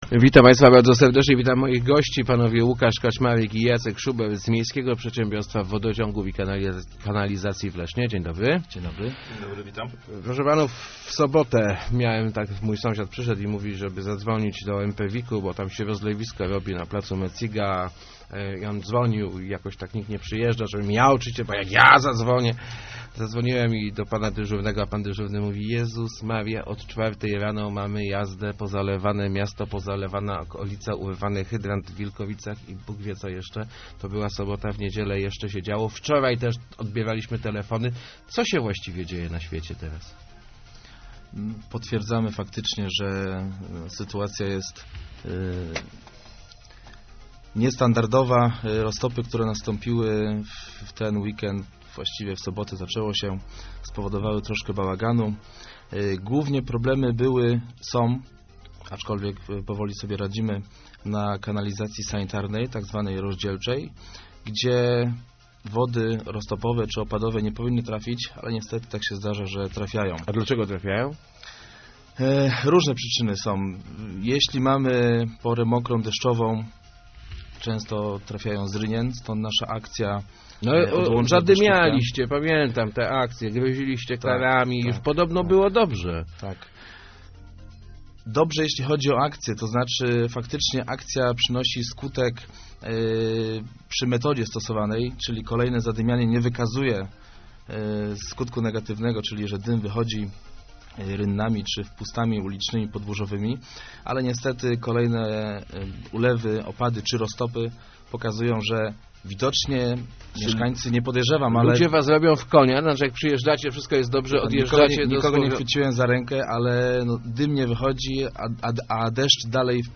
Nasza oczyszczalnia w ostatnich dniach dzia�a�a na granicy mo�liwo�ci technicznych - mówili w Rozmowach Elki